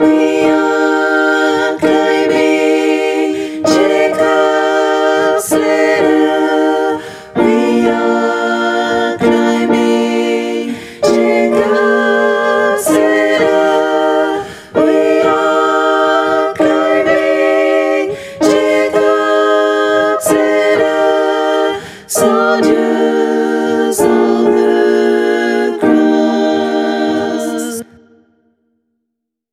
- Chant pour choeur mixte (SATB) a capella
MP3 versions chantées
Tutti